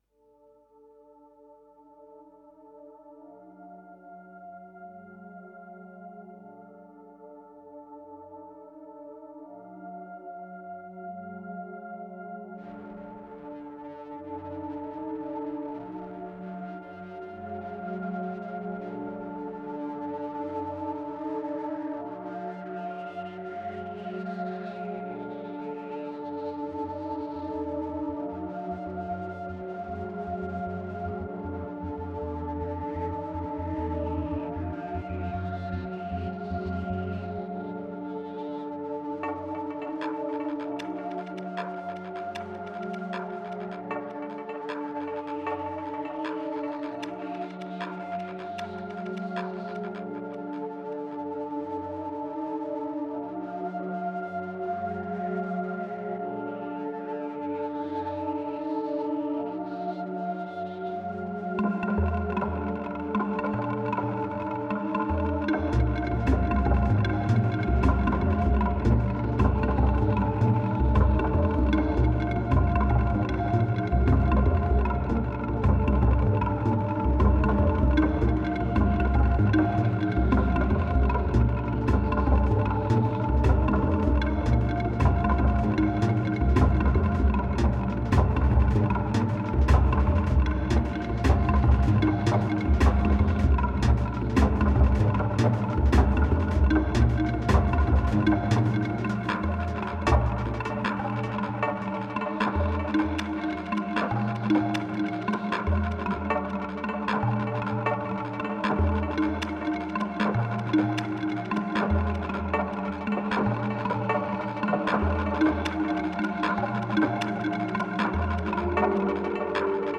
1990📈 - -2%🤔 - 77BPM🔊 - 2011-04-09📅 - -225🌟